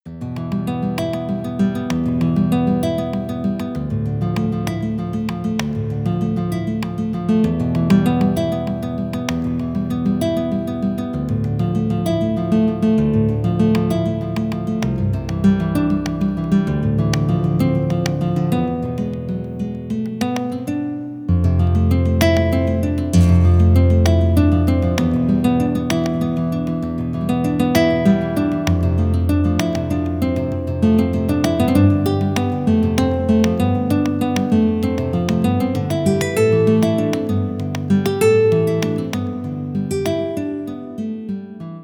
Guitarra española (bucle)